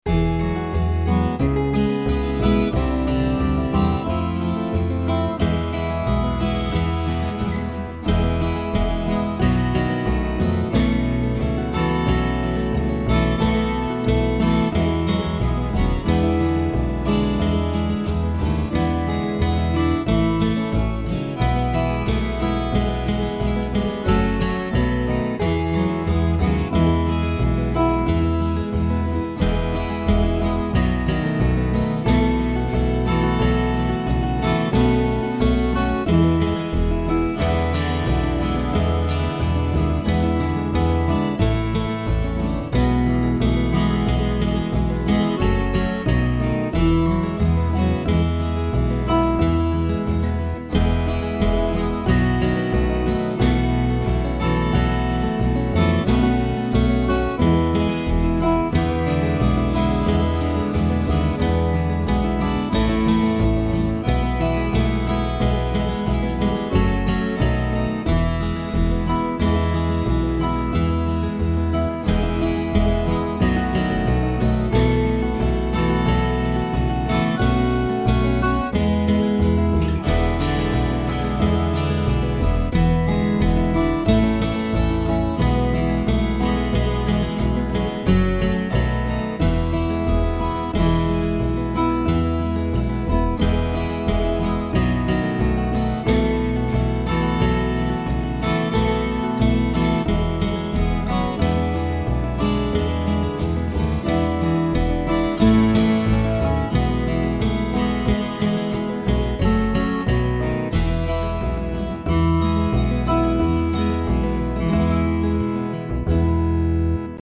A hymn
Alternate version with VST instruments: